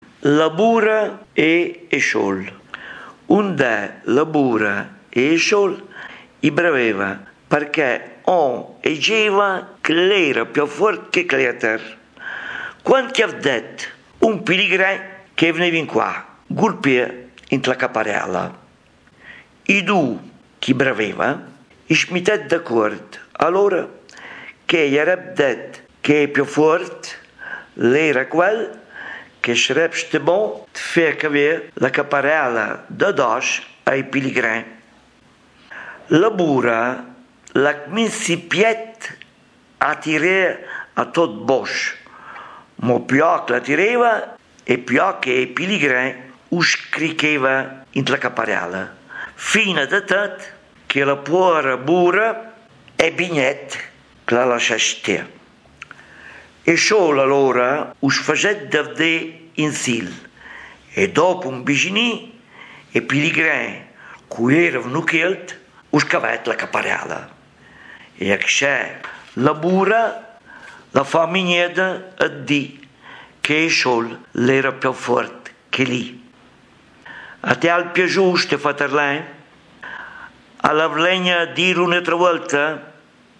Registrato a Faenza il 28 marzo 2011